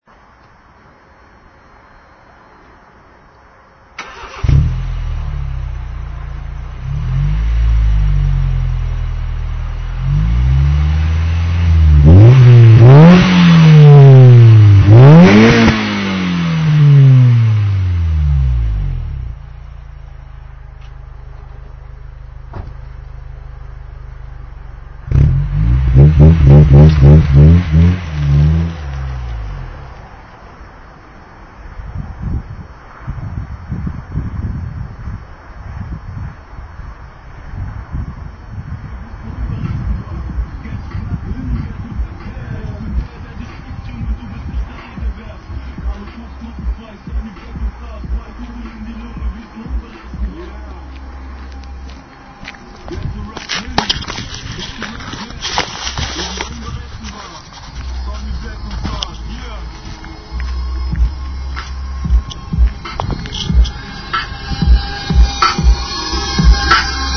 so ein kleines Soundfile von meiner auspuffanlage.... :)